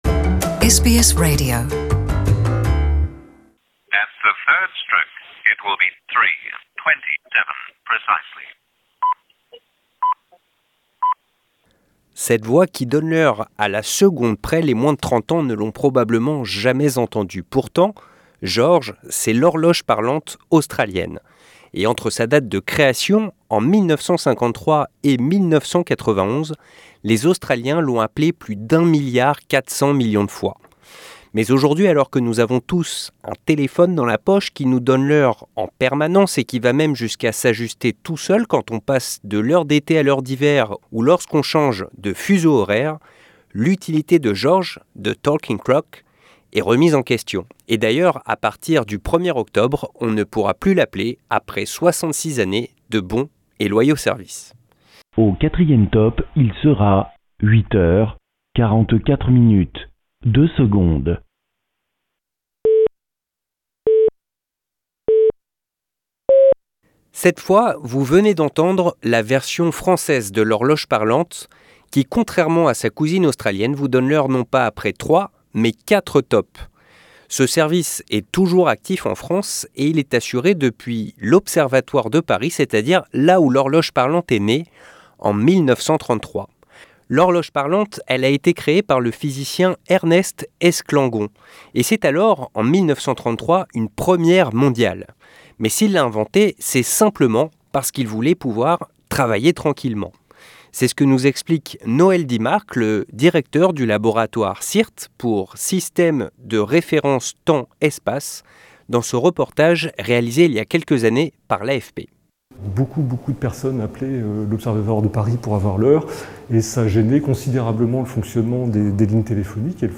Beep, beep, beep, beep"] This time you're listening to the French version of the talking clock, which, unlike its Australian cousin, gives you the time not after three, but four beeps.